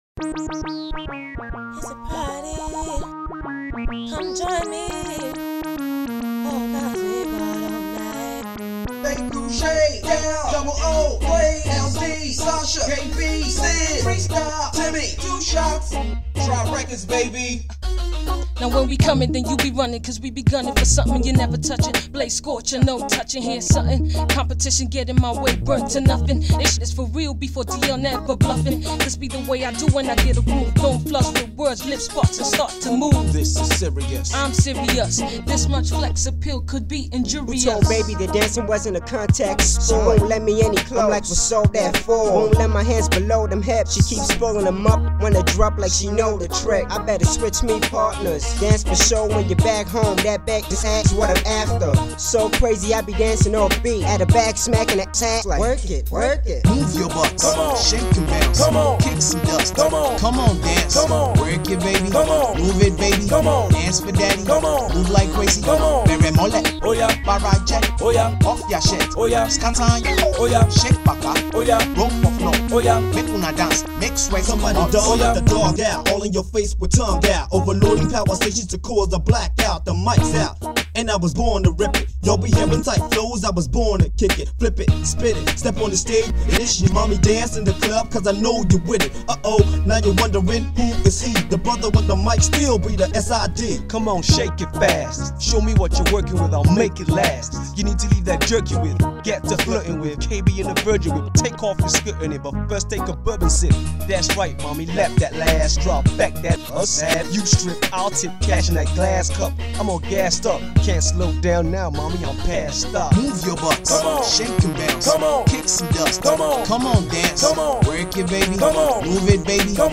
ten rappers spitting for like 30 seconds each.
hip-hop
With the tight chorus, the dance steps and the hard bars